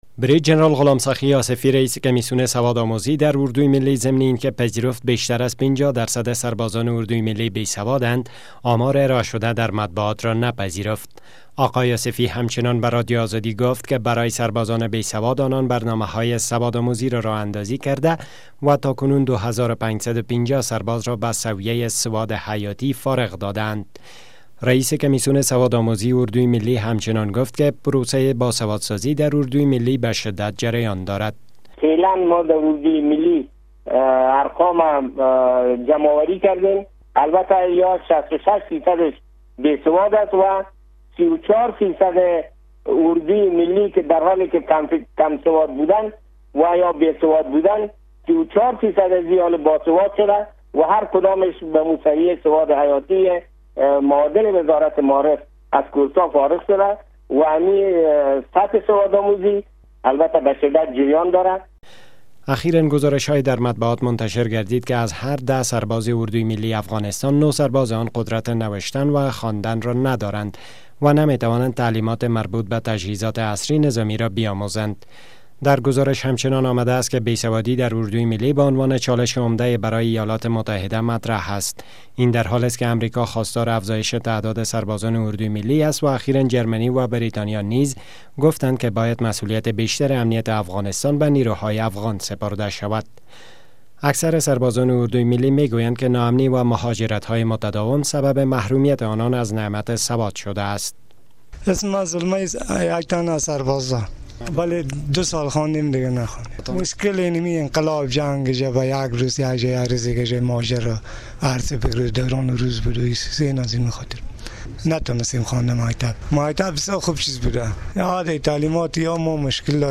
مصاحبه با جنرال غلام سخی آصفی